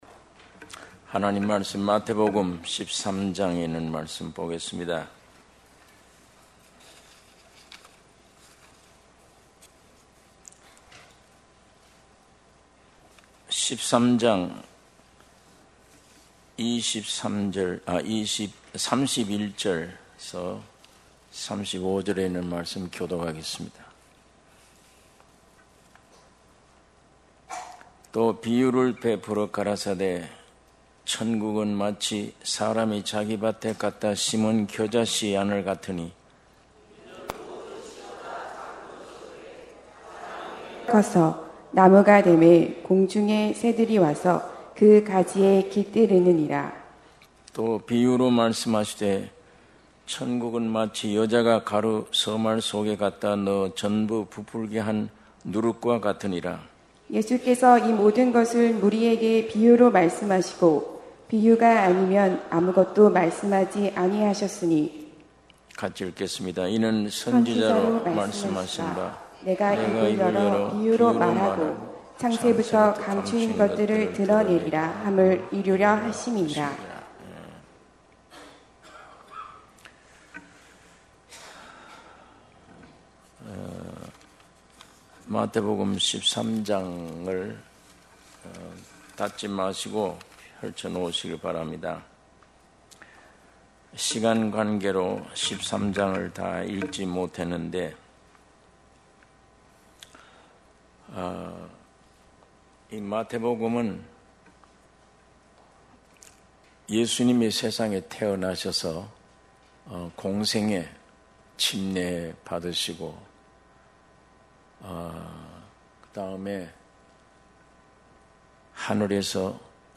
주일예배 - 마태복음 13장 31~35절